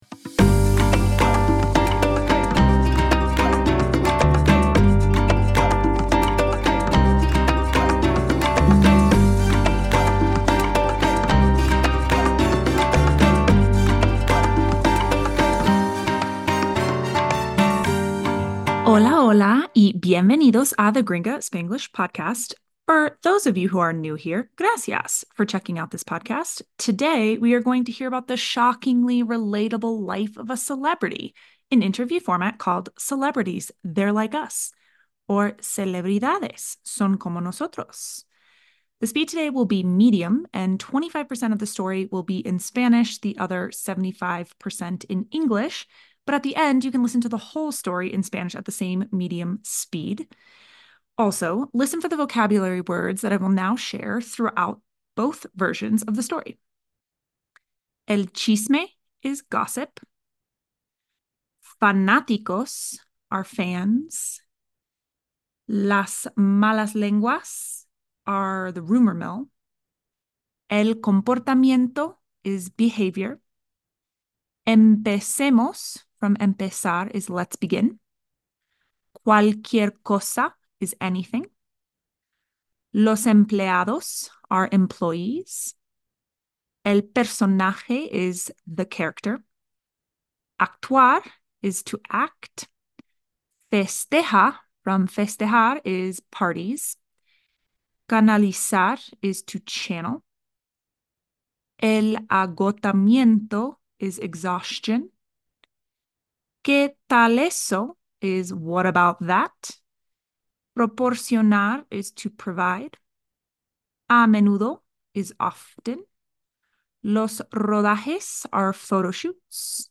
S03 E14 - Celebridades, ¡son como nosotros! - Medium Speed - 25% Spanish